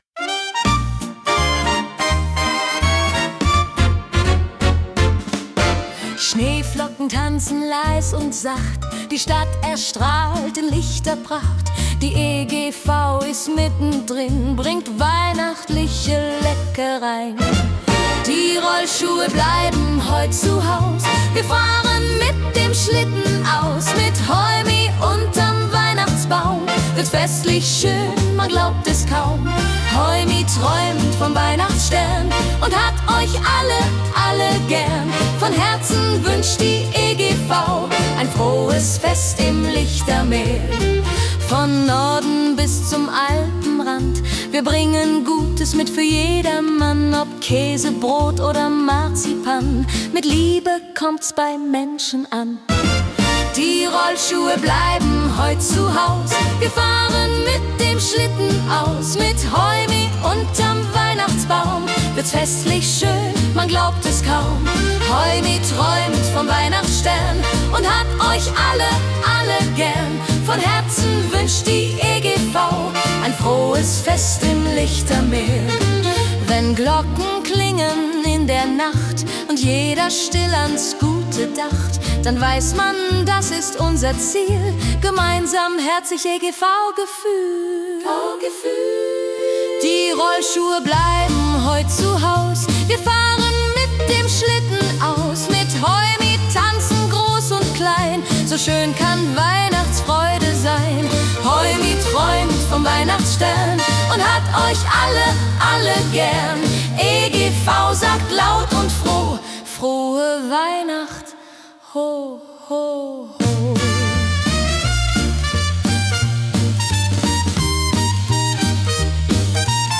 Weihnachtssong